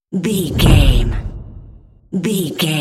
Dramatic hit deep fast trailer
Sound Effects
Atonal
Fast
heavy
intense
dark
aggressive